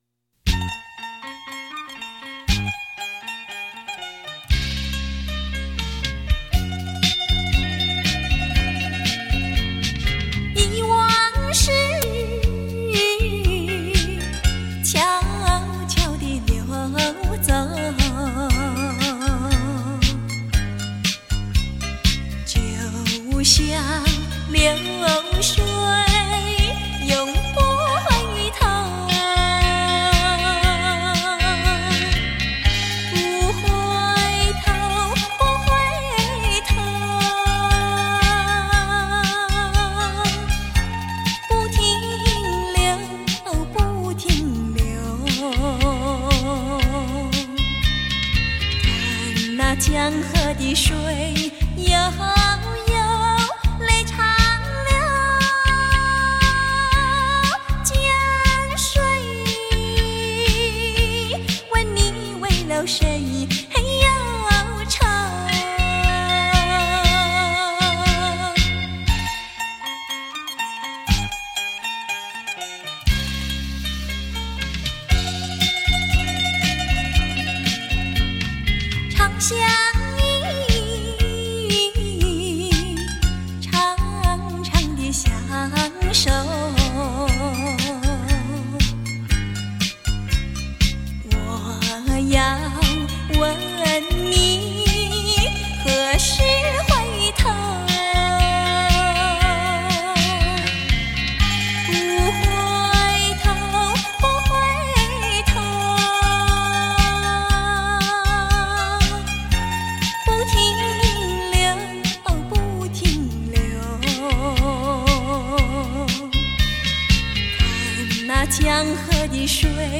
典雅温婉的歌声